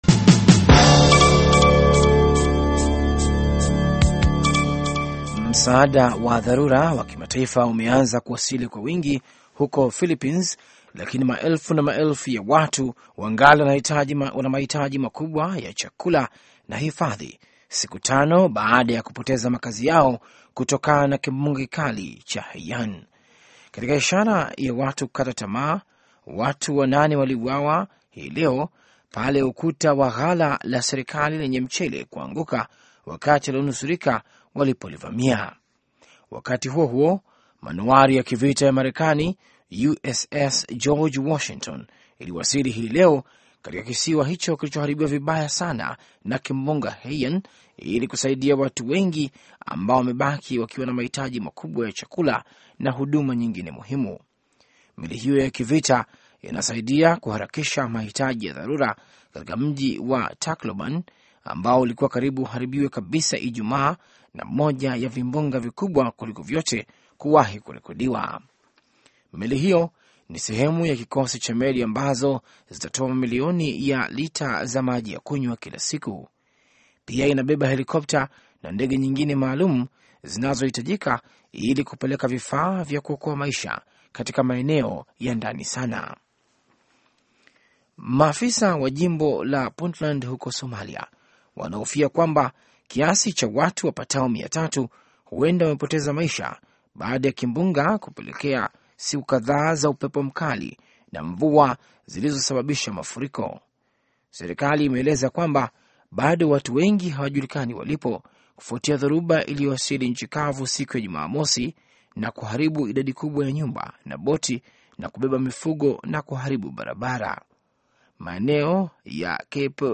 Taarifa ya Habari VOA Swahili - 6:30